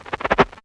Sparks.wav